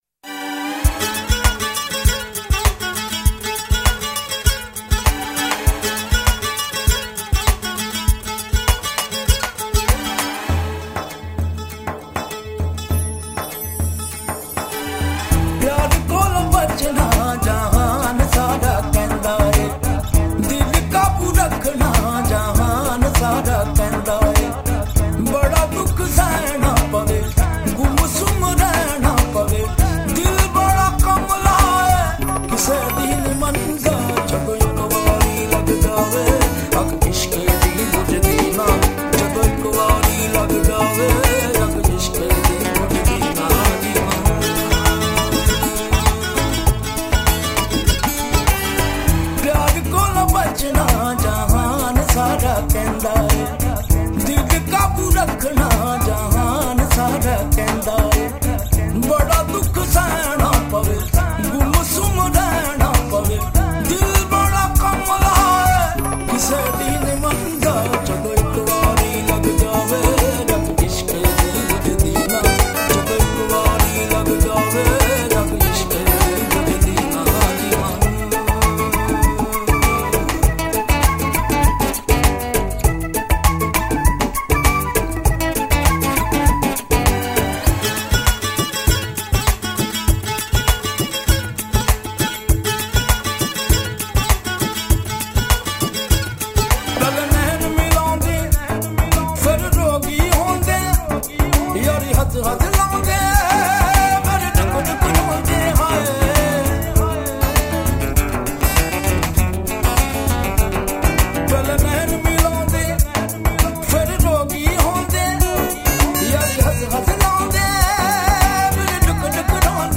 Sufi Collection
Punjabi Qawwali